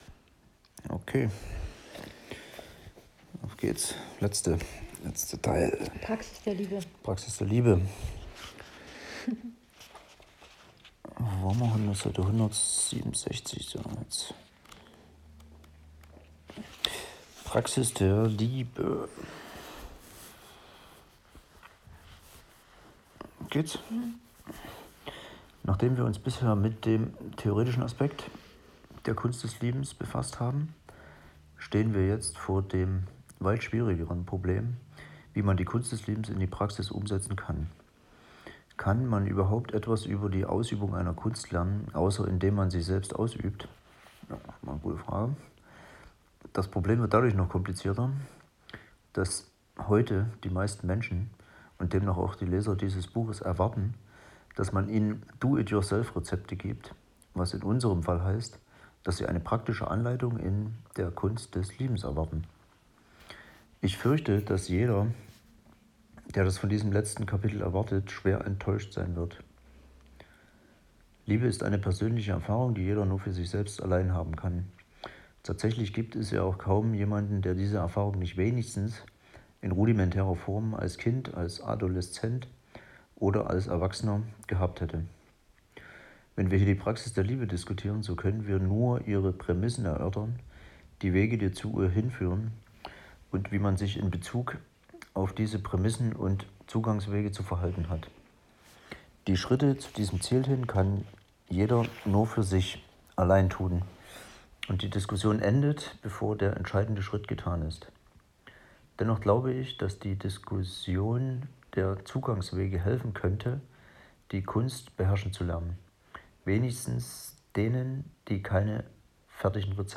Erich Fromm – Die Kunst des Liebens (Buchbesprechung zu zweit)